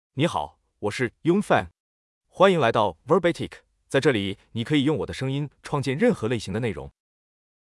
Yunfeng — Male Chinese (Mandarin, Simplified) AI Voice | TTS, Voice Cloning & Video | Verbatik AI
YunfengMale Chinese AI voice
Yunfeng is a male AI voice for Chinese (Mandarin, Simplified).
Voice sample
Listen to Yunfeng's male Chinese voice.
Yunfeng delivers clear pronunciation with authentic Mandarin, Simplified Chinese intonation, making your content sound professionally produced.